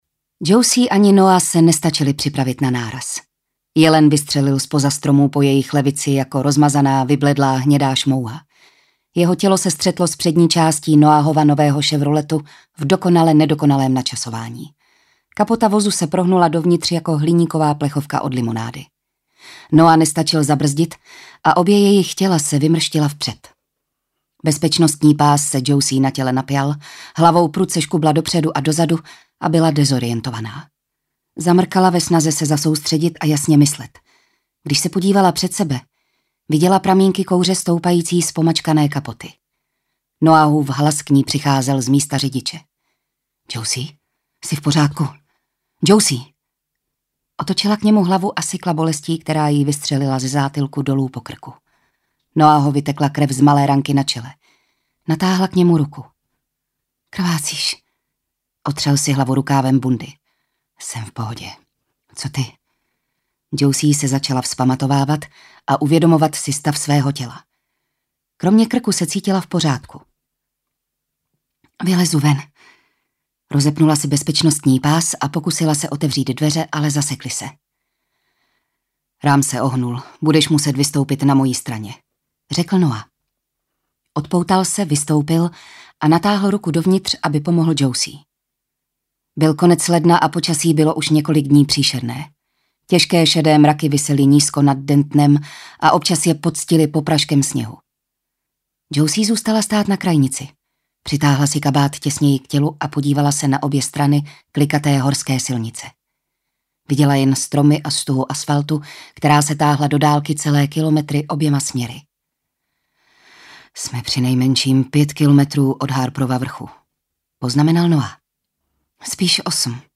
Audiokniha: Temný úkryt